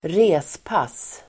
Ladda ner uttalet
respass substantiv, dismissal Uttal: [²r'e:spas:]